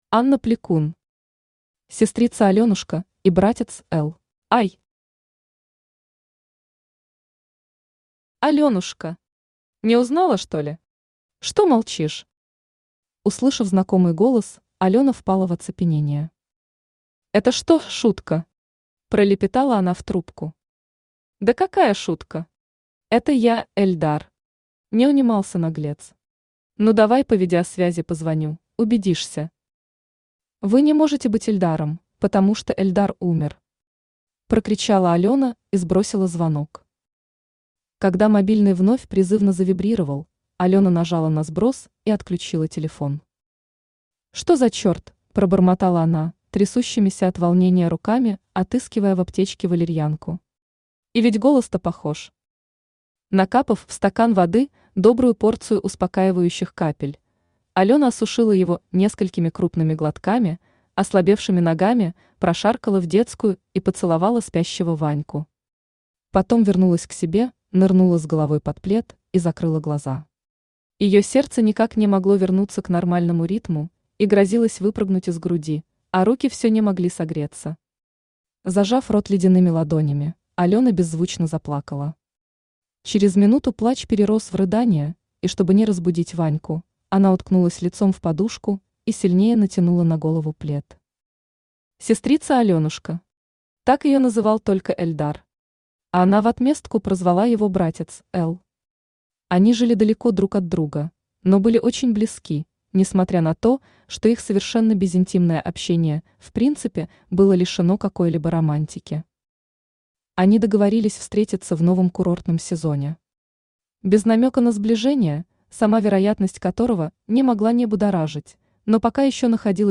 Аудиокнига Сестрица Аленушка и братец Эл | Библиотека аудиокниг
Aудиокнига Сестрица Аленушка и братец Эл Автор Анна Игоревна Плекун Читает аудиокнигу Авточтец ЛитРес.